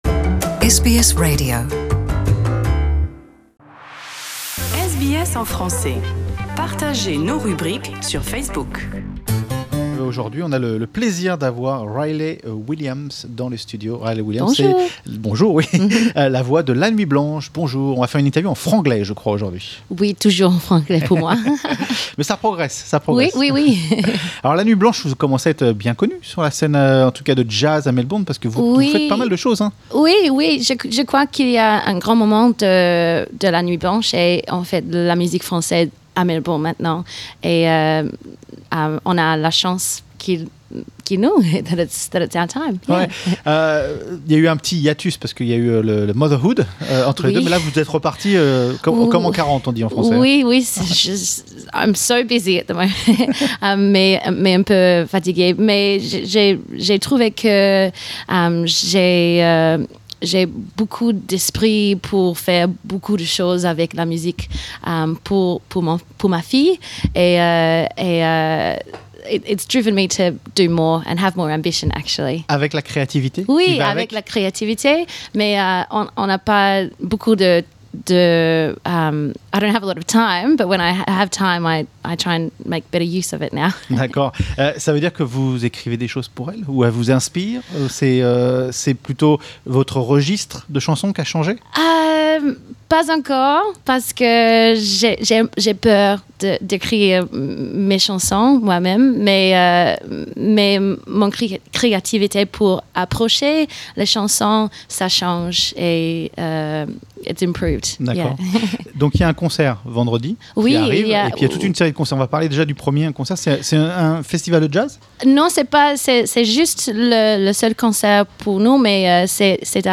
Cette interview date de novembre 2018.